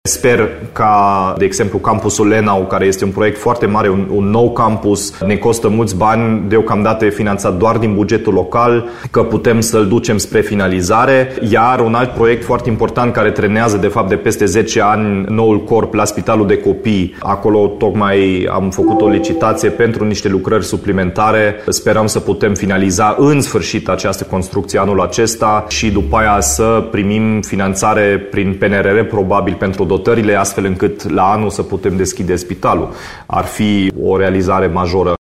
Primarul Timișoarei, Dominic Fritz, a declarat la Radio Timișoara că noul spital de copii ar putea fi pus în funcțiune abia anul viitor, după dotarea unității medicale cu echipamente: